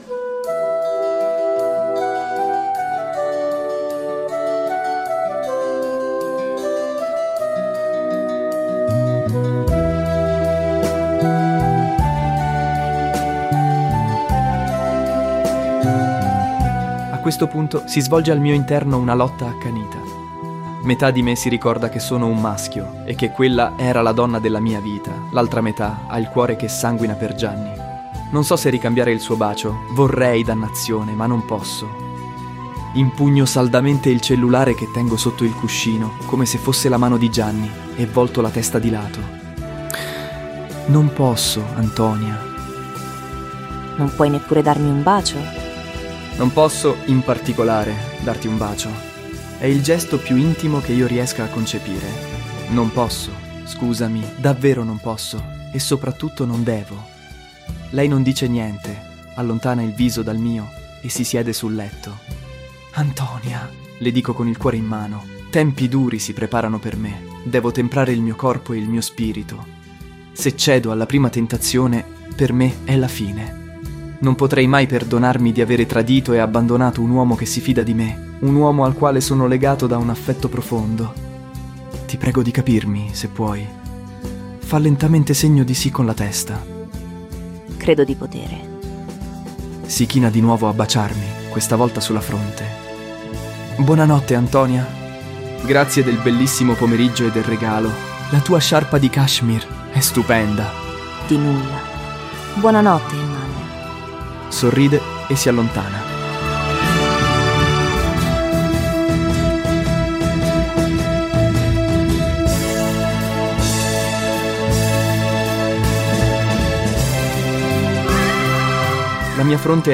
Podcast Novel